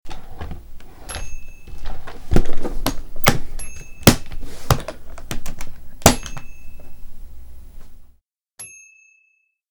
Suitcase Close Sound Effect
A suitcase being closed and latches being locked.
SuitcaseClose.mp3